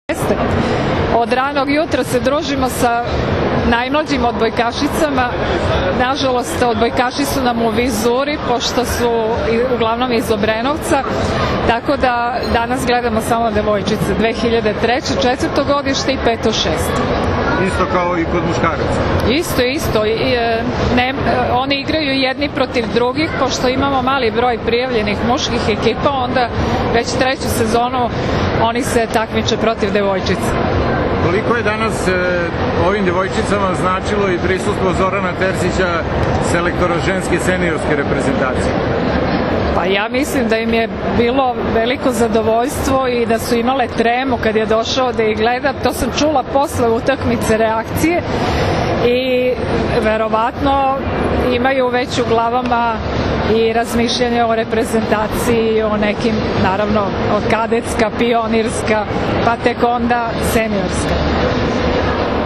4. MEĐUNARODNI SAJAM SPORTA 2015. – “SUSRET ŠAMPIONA”
IZJAVA